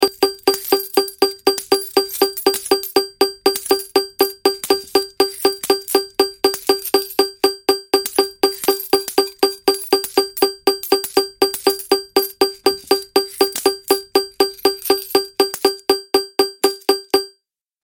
دانلود آهنگ هشدار 25 از افکت صوتی اشیاء
دانلود صدای هشدار 25 از ساعد نیوز با لینک مستقیم و کیفیت بالا
جلوه های صوتی